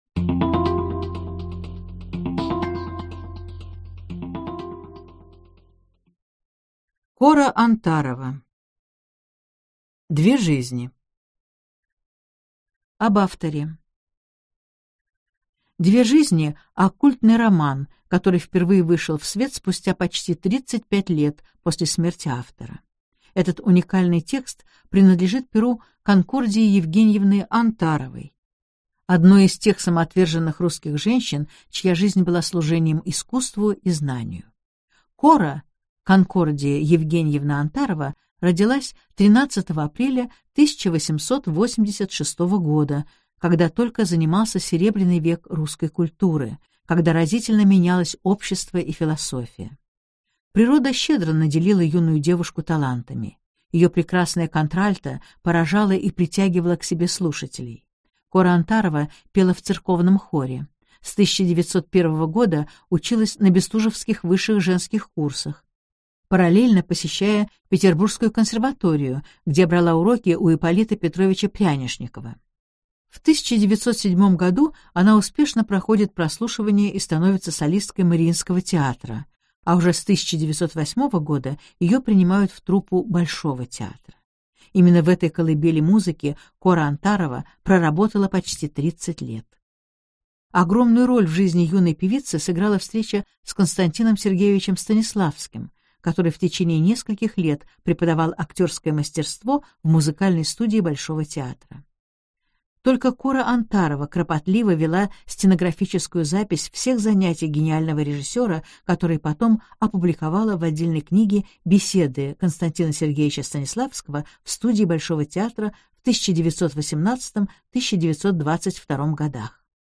Аудиокнига Две жизни. Все части. Сборник в обновленной редакции | Библиотека аудиокниг